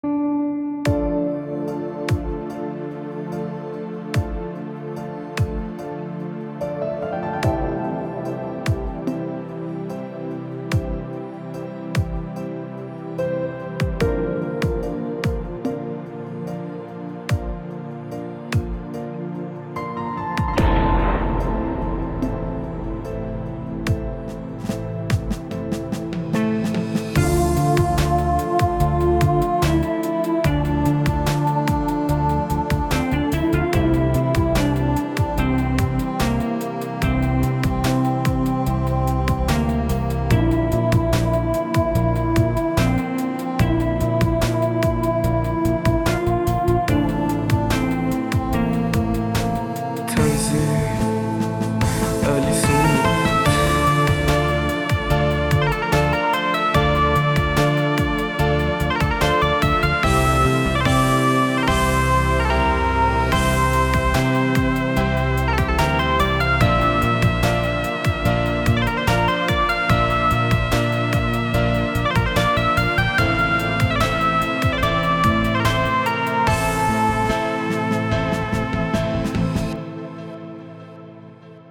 • آهنگسازی رپ و پاپ راک اند بی در زاهدان
دانلود بیت رپ